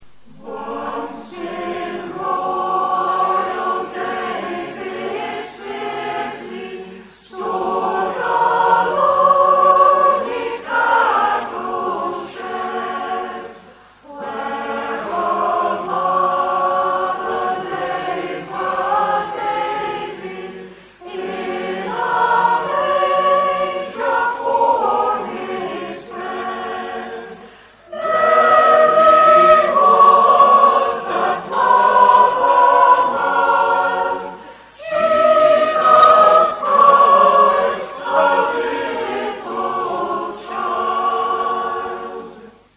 Village Carols from Beeston, Nottinghamshire